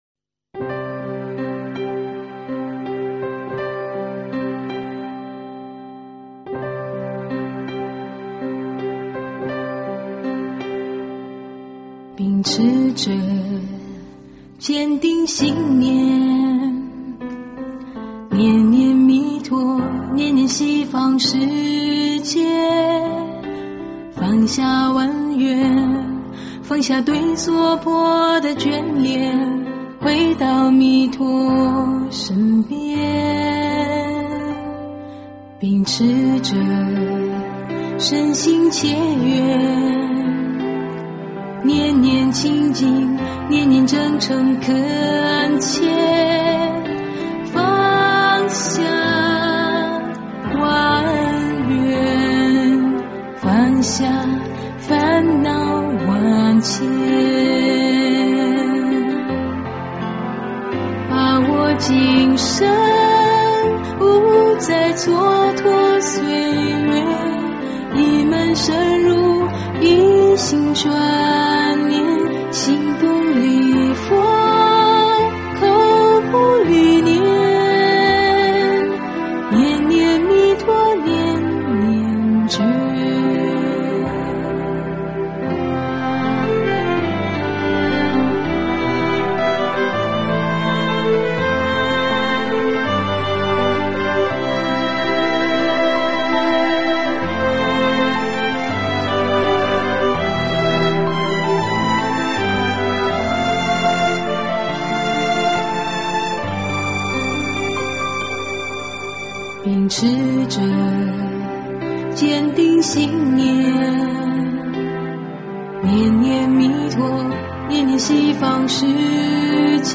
佛音 诵经 佛教音乐 返回列表 上一篇： 佛说除一切疾病陀罗尼经 下一篇： 楞严咒快诵 相关文章 24.